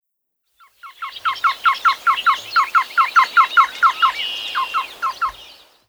Black-billed Cuckoo
BIRD CALL: TRIPLICATE “KU-KU-KU” CALL REPEATED MULTIPLE TIMES.
Black-billed-cuckoo-call.mp3